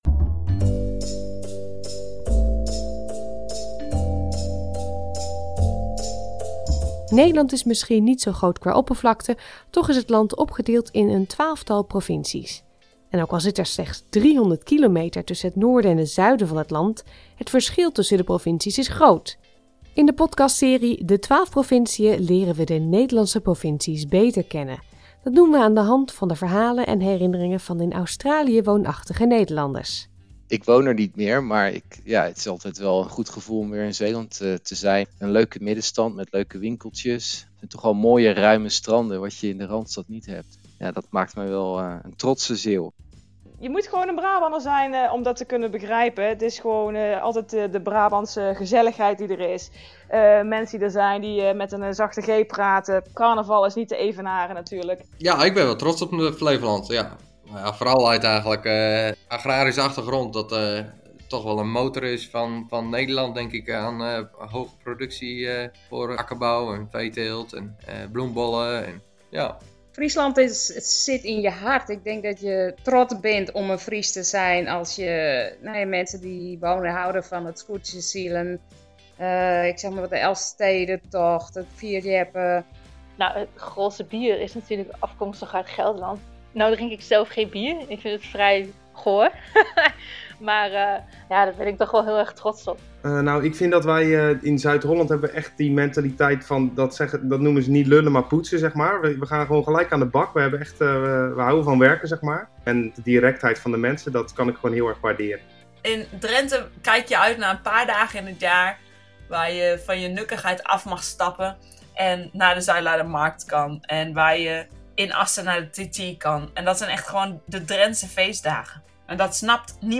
En ook al zit er slechts 300 kilometer tussen het noorden en het zuiden van het land, het verschil tussen de provincies is groot. In de podcast serie De 12 Provinciën leren we de Nederlandse provincies beter kennen dankzij de verhalen en herinneringen van in Australië woonachtige Nederlanders.